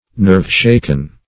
Nerve-shaken \Nerve"-shak`en\ (n[~e]rv-sh[=a]k"'n)